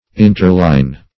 interline - definition of interline - synonyms, pronunciation, spelling from Free Dictionary